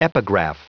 Prononciation du mot epigraph en anglais (fichier audio)
Prononciation du mot : epigraph